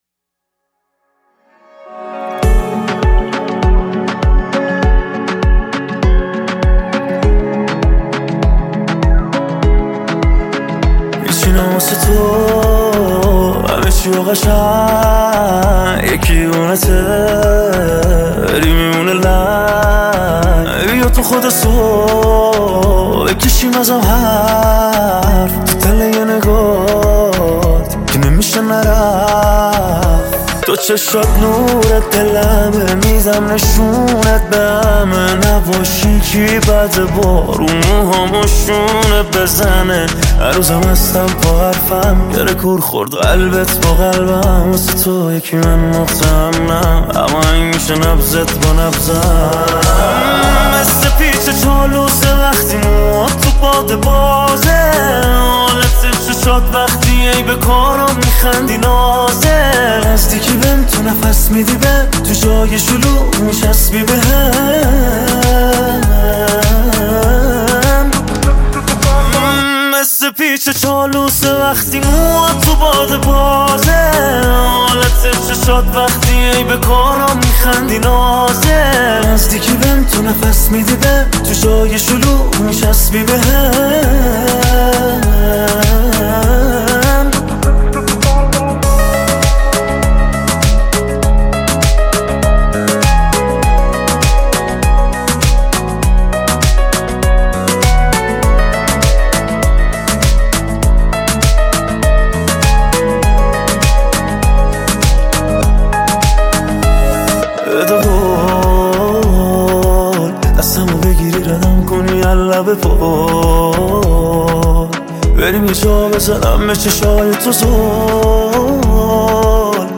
پاپ عاشقانه